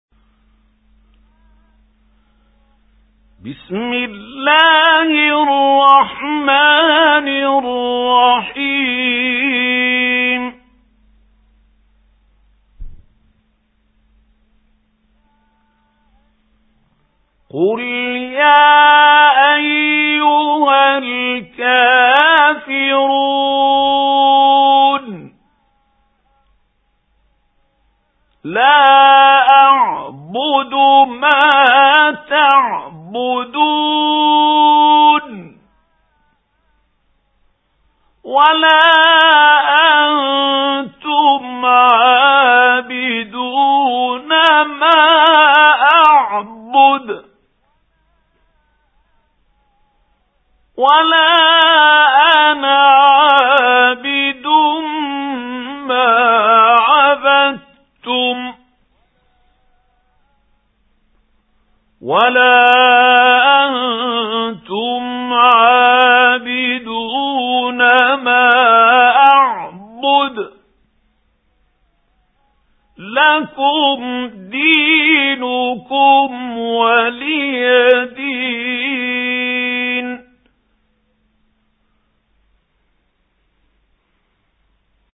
سُورَةُ الكَافِرُونَ بصوت الشيخ محمود خليل الحصري